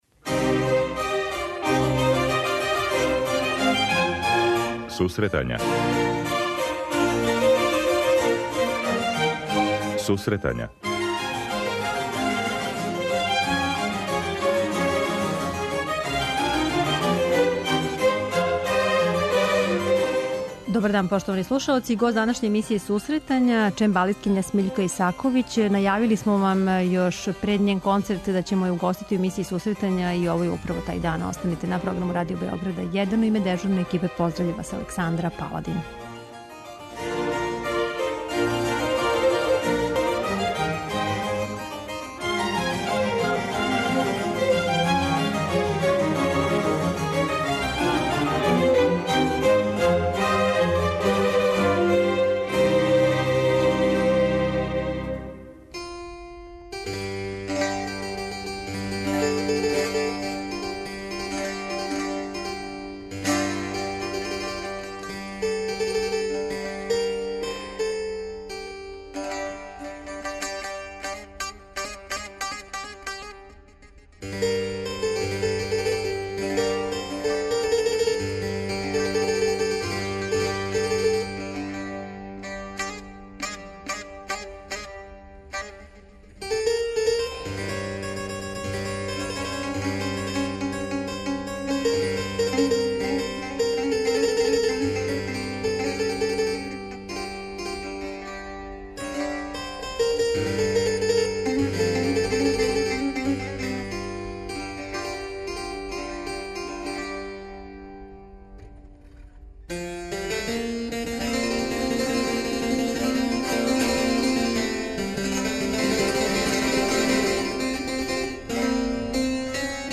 преузми : 25.47 MB Сусретања Autor: Музичка редакција Емисија за оне који воле уметничку музику.